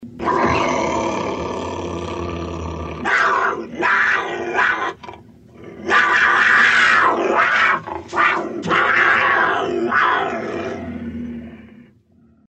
Хорошие варианты звуков рычания и шипения дикой пумы в mp3 формате.
rychanie-pumy-2.mp3